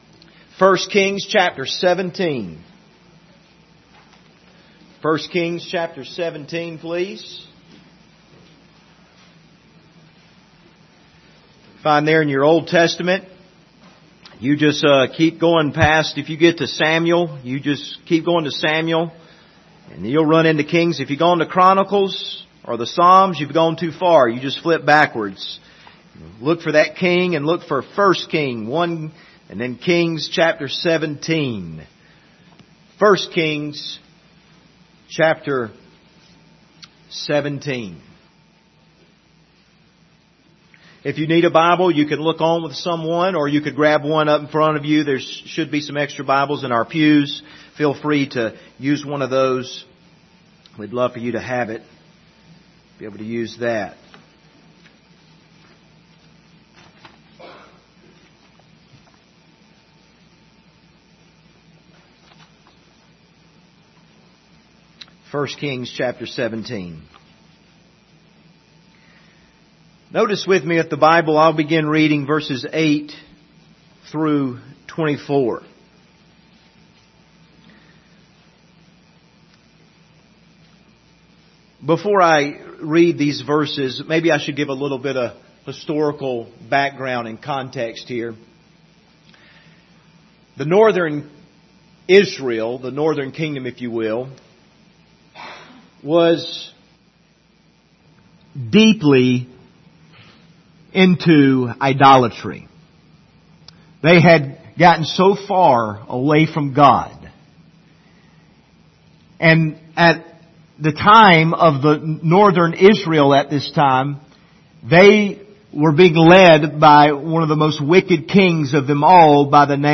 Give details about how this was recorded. Passage: I Kings 17 Service Type: Sunday Morning